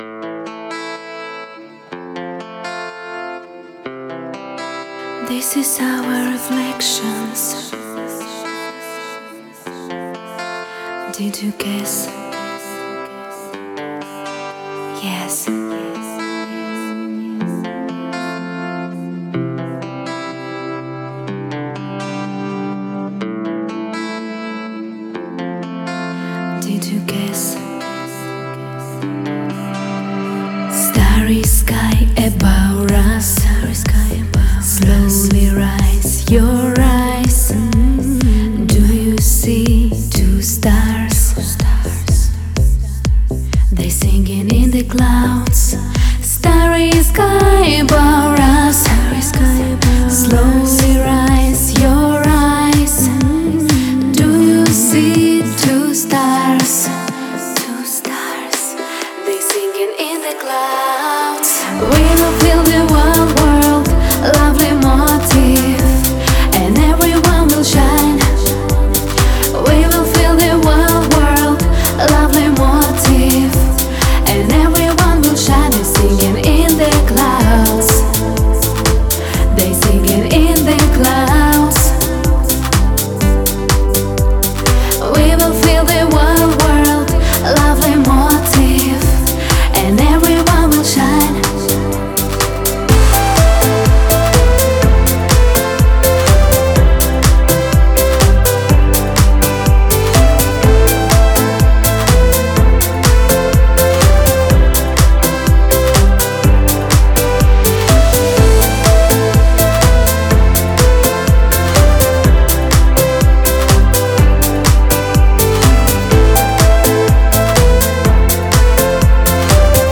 это завораживающий трек в жанре deep house